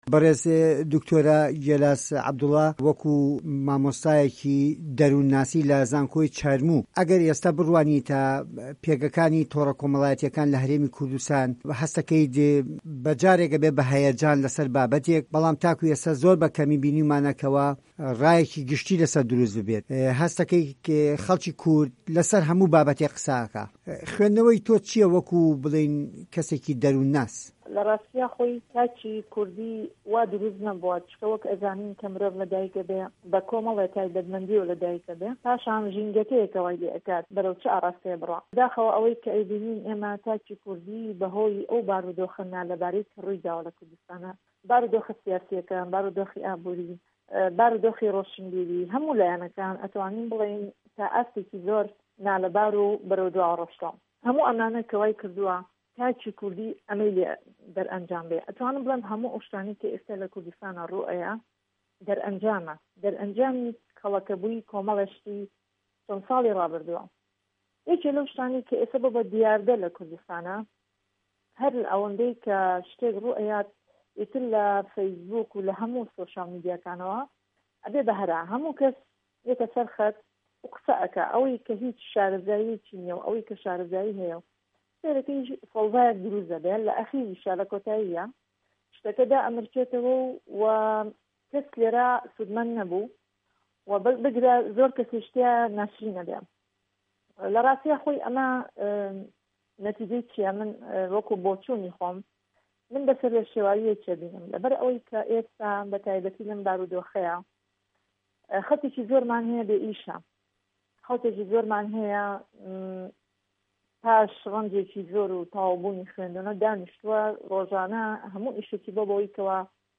وتووێژ